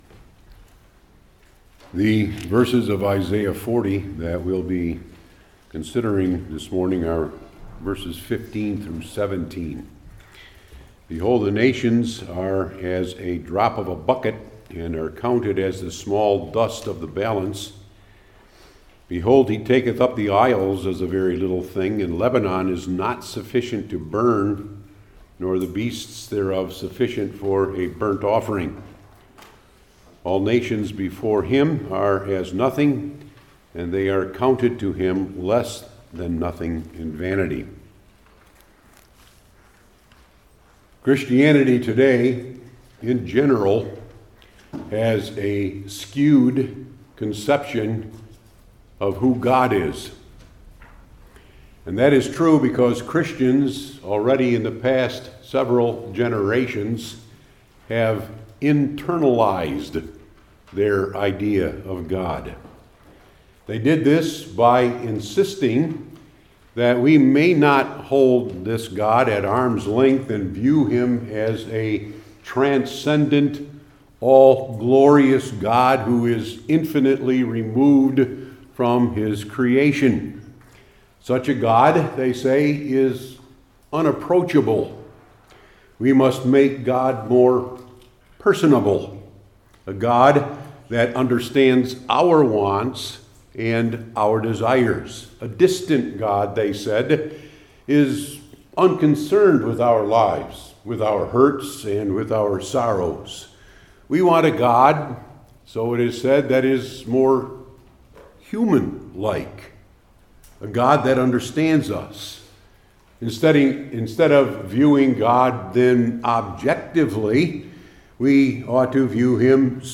Isaiah 40:15-17 Service Type: Old Testament Sermon Series I. Identified II.